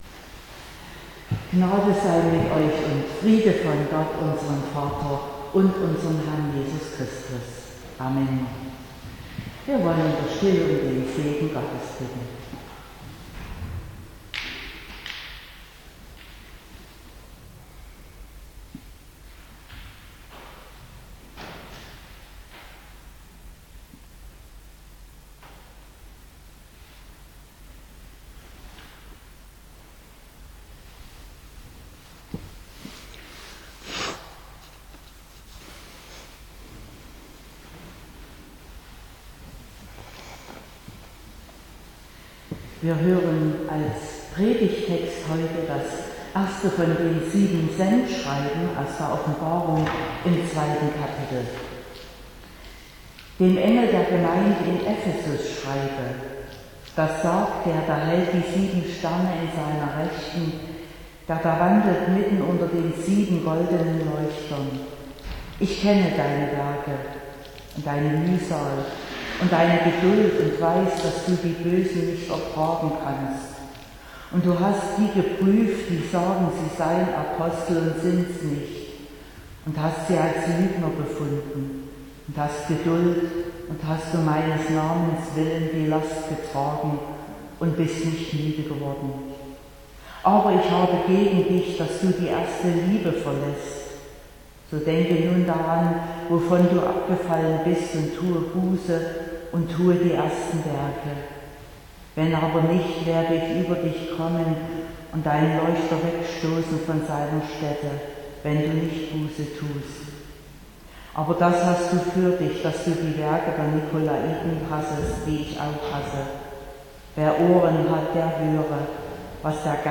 04.12.2022 – Gottesdienst
Predigt (Audio): 2022-12-04_Wenn_Glaube_gewoehnlich_geworden_ist.mp3 (29,4 MB)